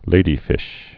(lādē-fĭsh)